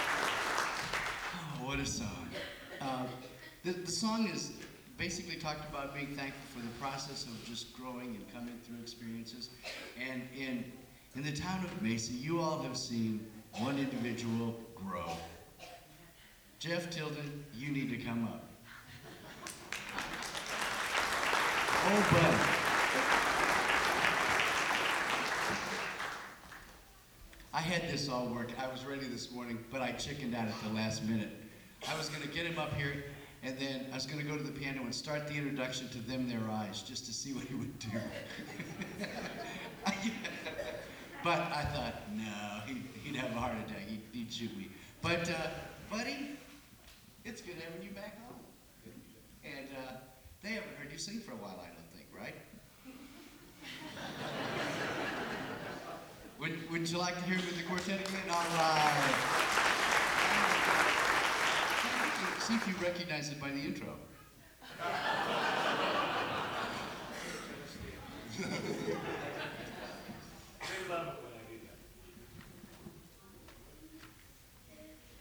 Genre: Gospel | Type: Specialty